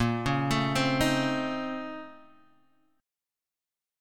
BbmM11 chord